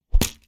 Close Combat Attack Sound 25.wav